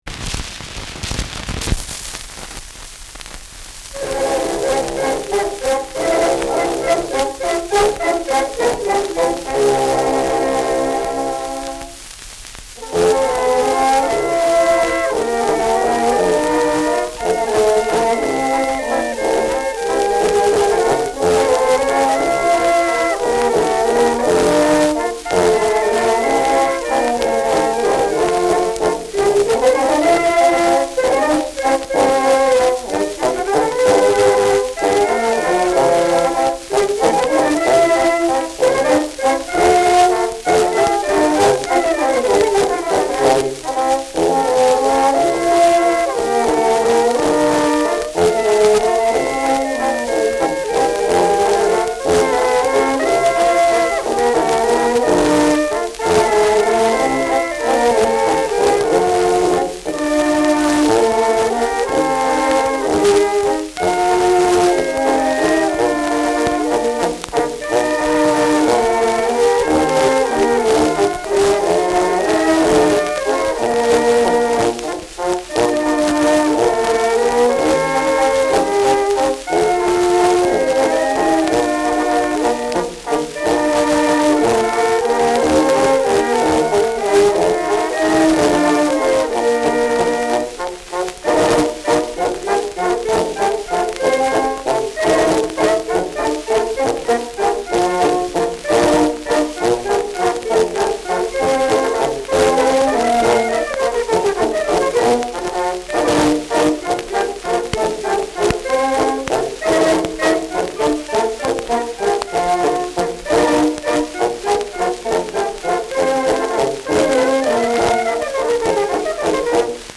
Schellackplatte
Stark abgespielt : Erhöhtes Grundrauschen : Teils verzerrt : Vereinzelt starkes Knacken : Leichtes Leiern
Truderinger, Salzburg (Interpretation)